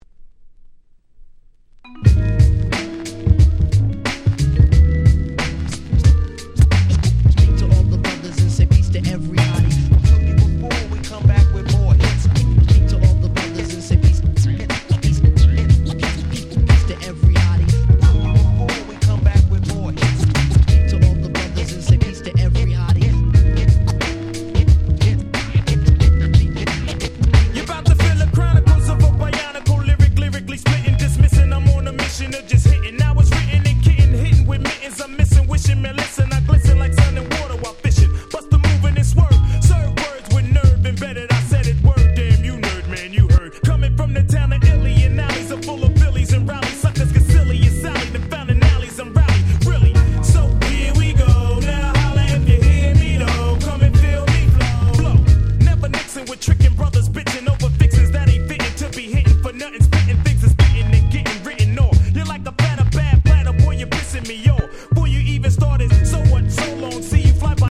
95' Hip Hop Classic !!